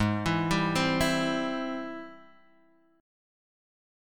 G#+M7 chord